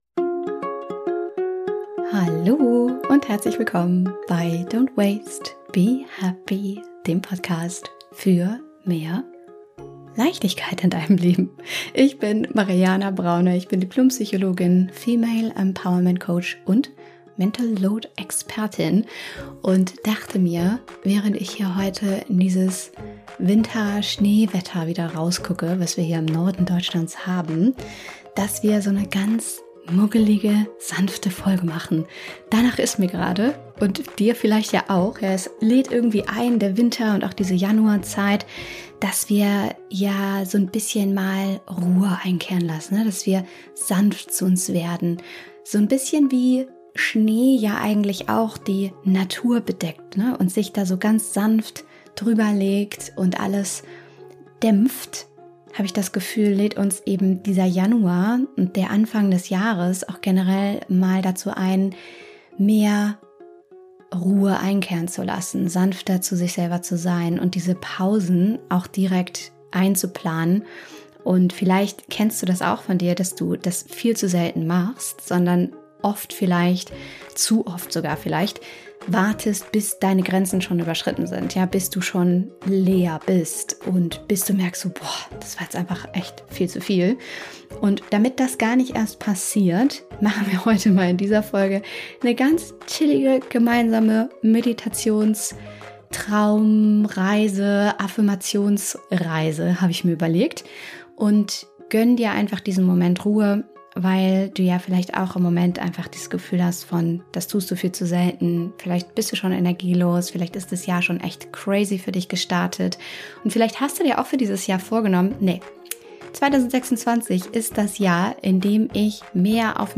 Meditation um sanft ins neue Jahr zu starten - ohne Druck, ohne Müssen ~ don't waste, be happy - Dein Podcast für mehr Leichtigkeit in deinem Leben